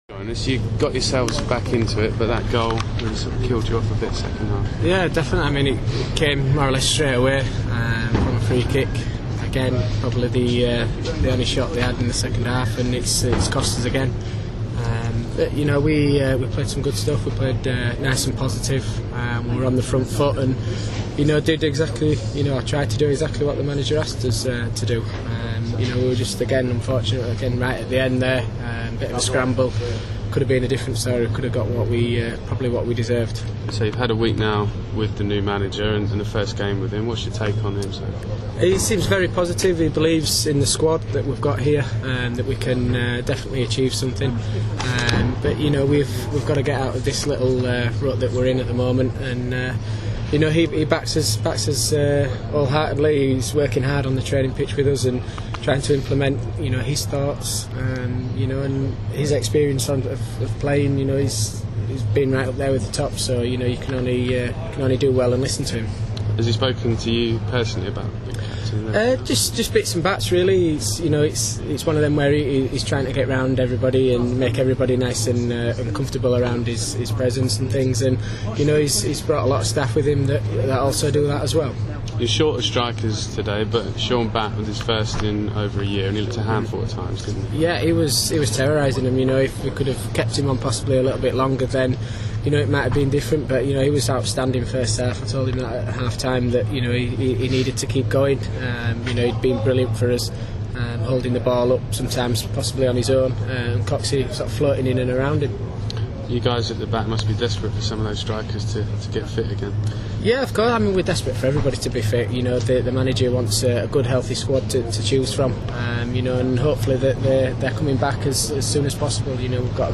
speaking after Orient vs Peterborough